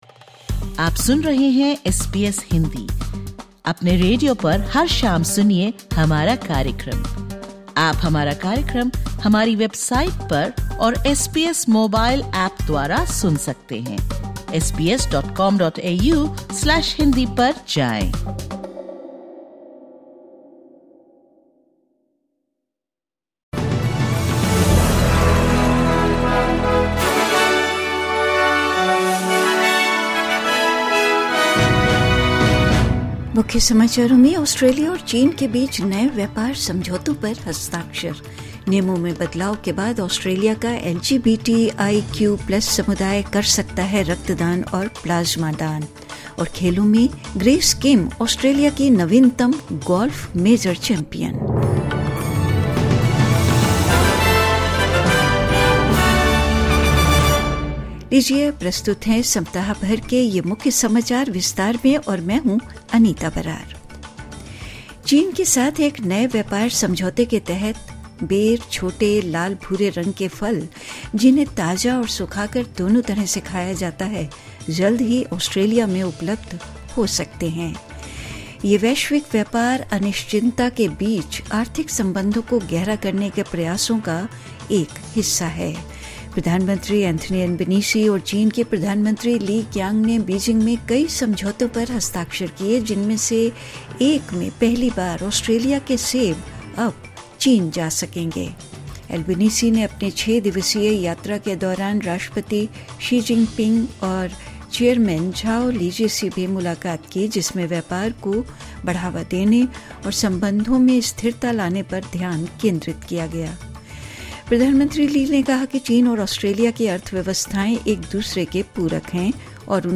Listen to the Weekly News Wrap of the week ending 18/07/2025